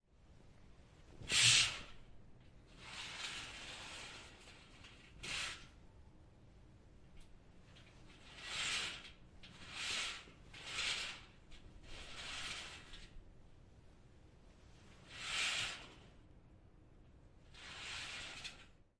浴室内的声音 " H2浴帘
描述：快速打开窗帘进入浴室